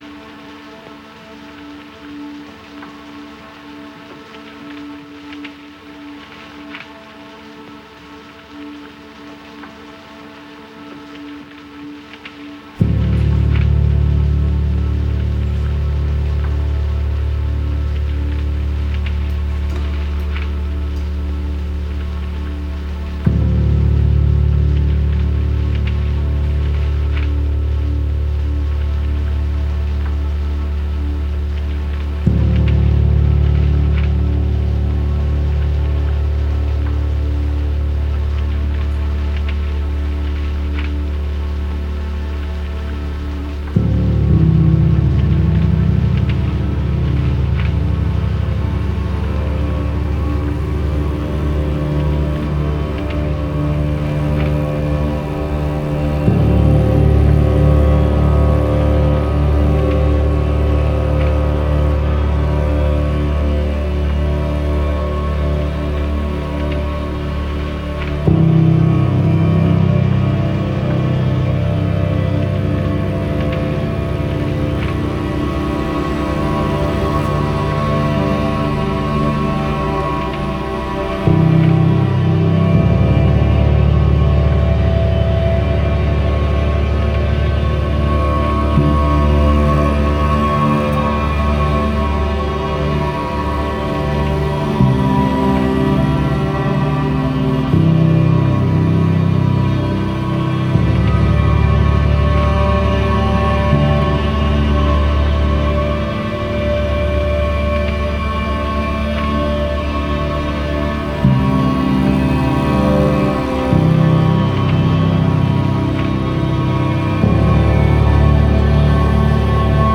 your soundtrack for crawling into a dark hole to die.
is often quite beautiful